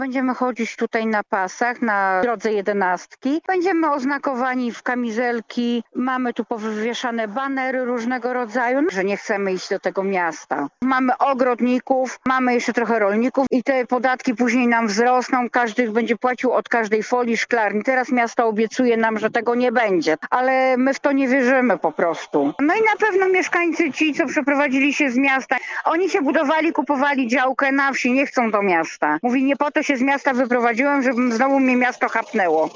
Mówi Marzena Żebrowska, sołtys Kretomina.